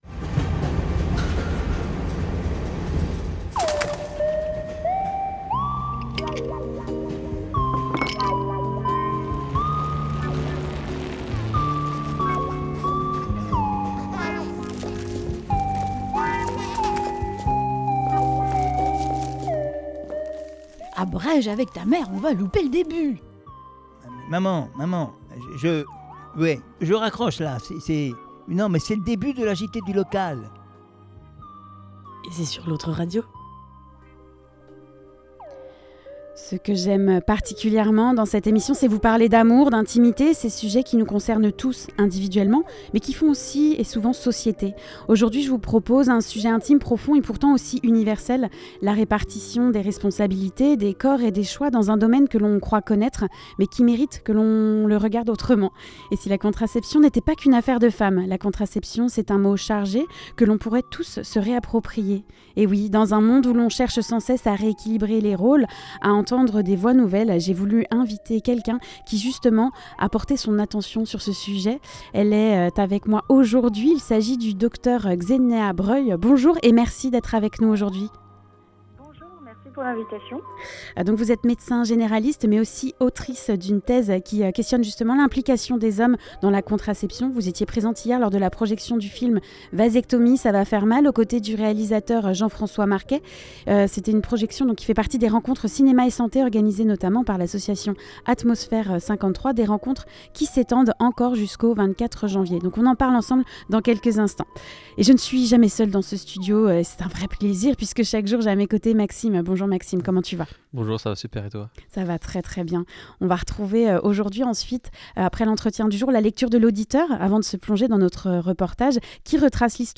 On se rend à la Boulangerie La Tentation à Saint Berthevin, elle a reçu le prix de la meilleure galette Frangipane 2025 organisée par la fédération des Boulangers de la Mayenne.